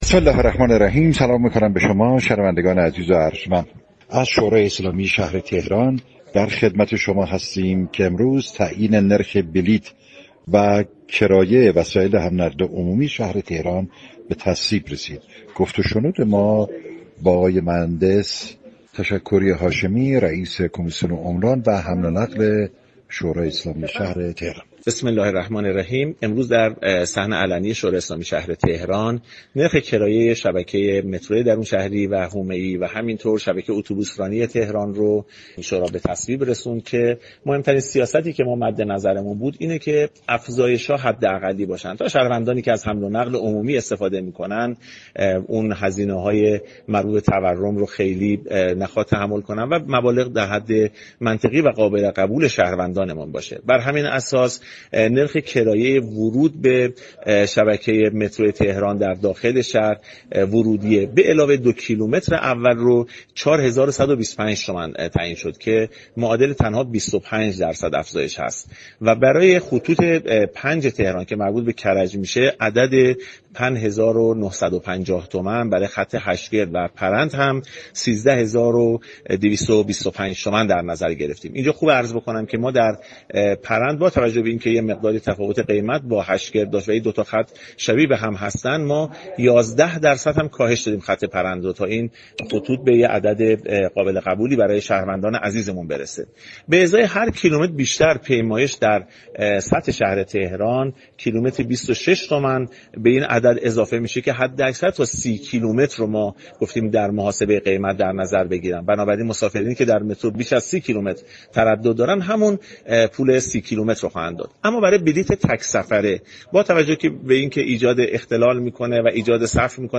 به گزارش پایگاه اطلاع رسانی رادیو تهران، سید جعفر تشكری هاشمی رئیس كمیسیون عمران و حمل و نقل شورای شهر تهران در گفت و گو با «بام تهران» اظهار داشت: مهمترین سیاست شورای شهر در تعیین نرخ كرایه حمل و نقل عمومی افزایش حداكثری كرایه‌ها بود.